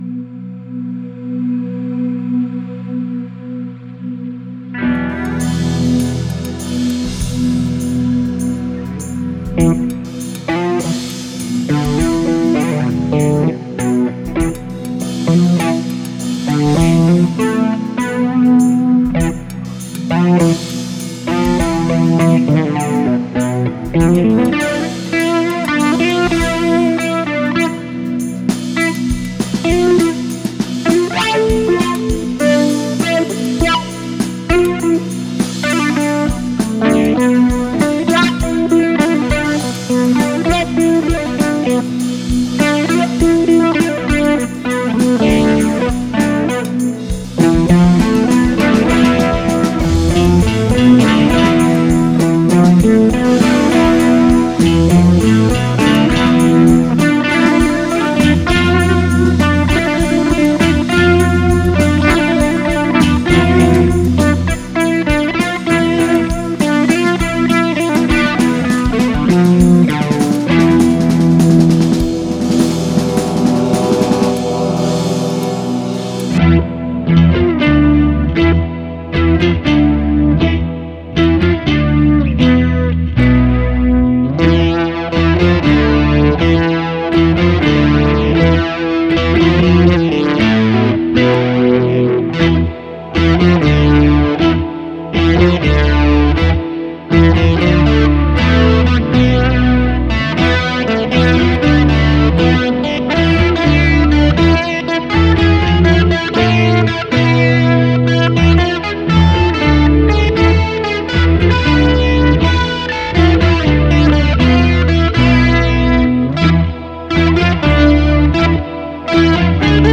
Musikalisch kommen arabische, indische und japanische Skalen zum Einsatz. Die klangliche Einfärbung ist indisch, die Komposition jedoch westlich, sodass die Schublade Ethno-Fusion-Rock am besten passt.
Die Musik zeichnet ein dramatisches Bild vom Missbrauch des sechsten Chakras.